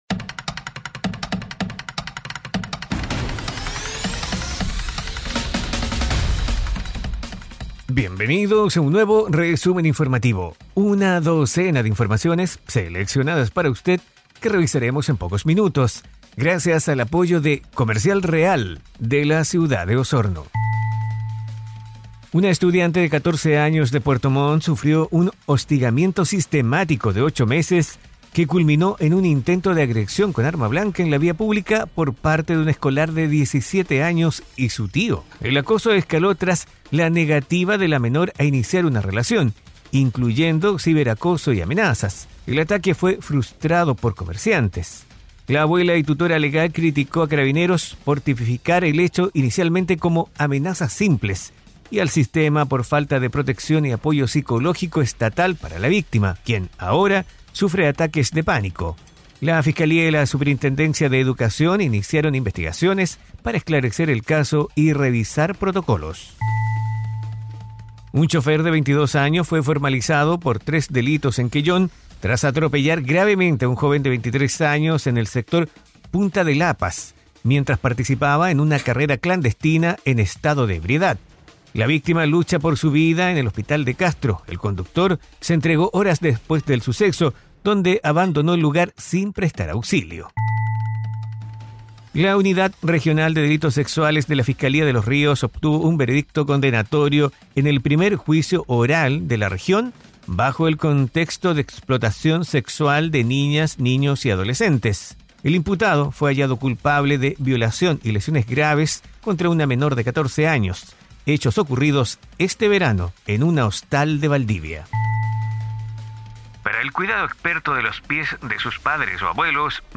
Resumen Informativo 🎙 Podcast 28 de noviembre de 2025